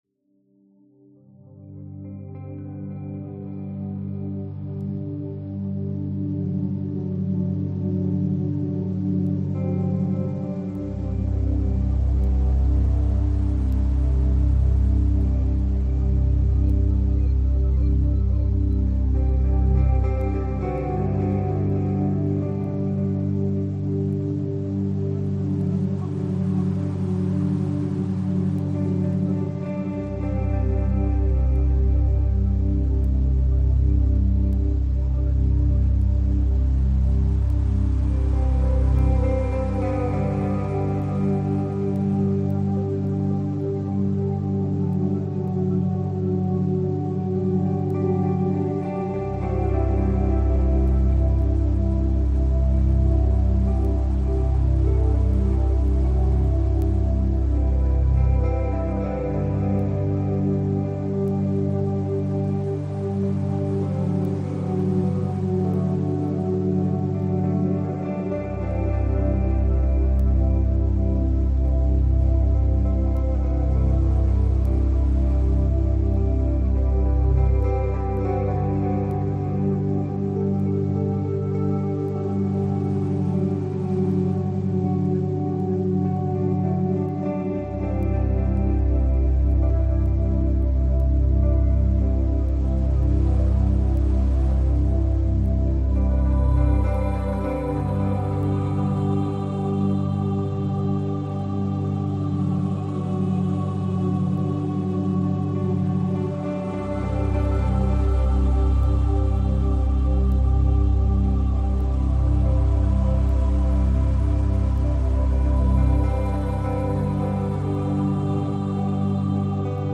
Успокаивающая музыка без слов для быстрого засыпания